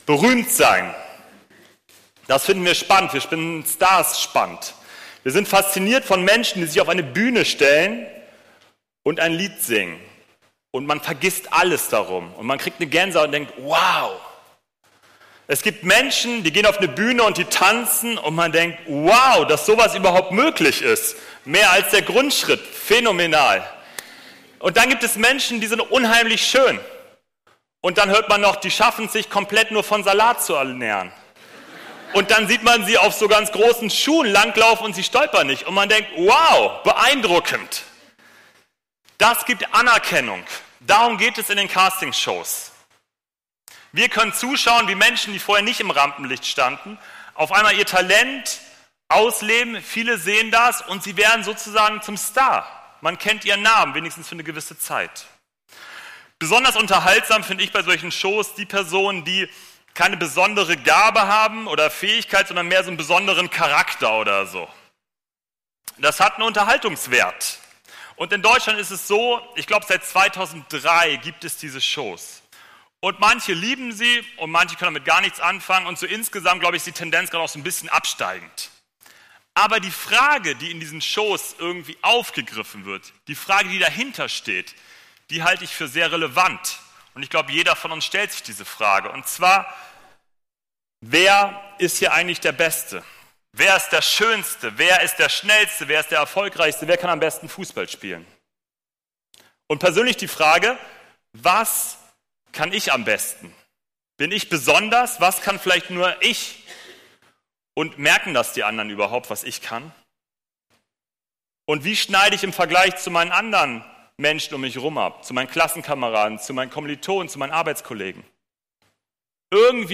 Gottesdienst
Predigttext: Jesaja 47,1